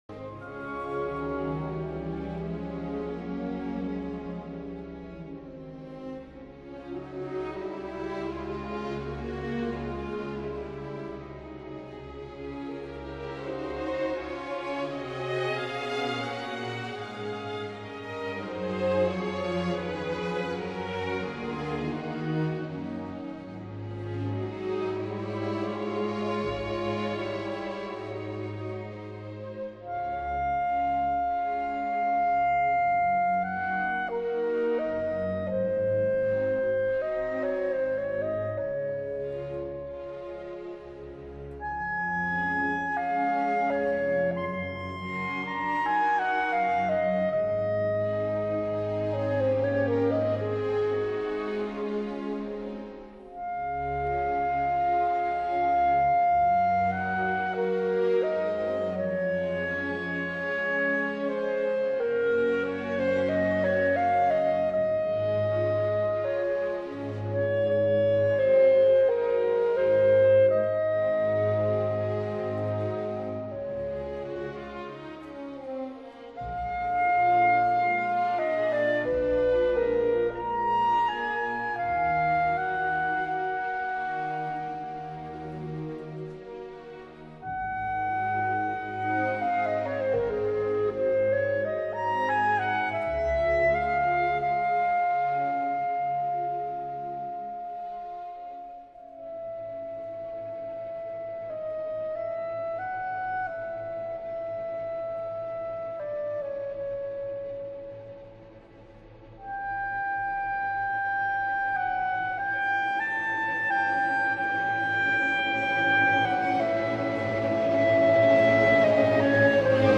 clarinet & orchestra